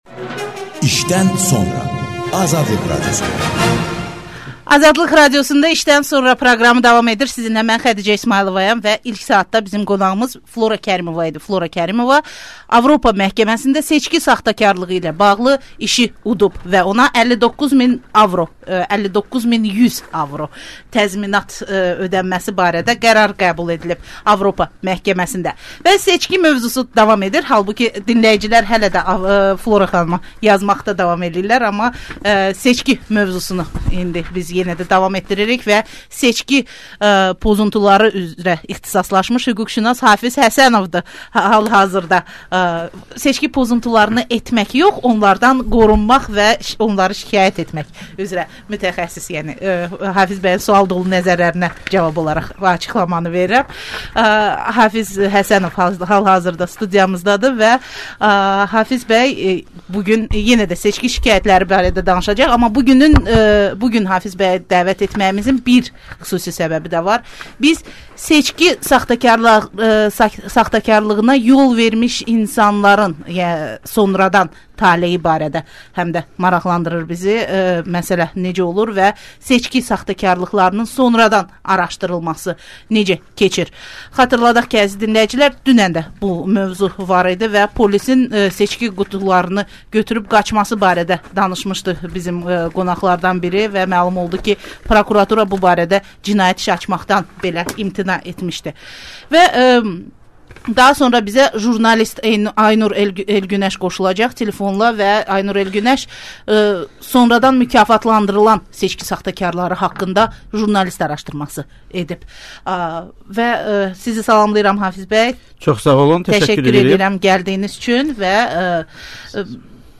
Xalq artisti Flora Kərimova ilə söhbət